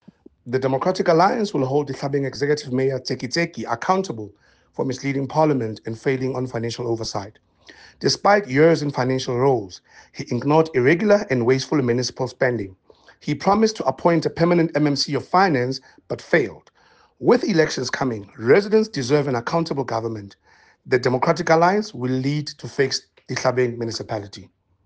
Sesotho soundbites by Cllr Eric Motloung and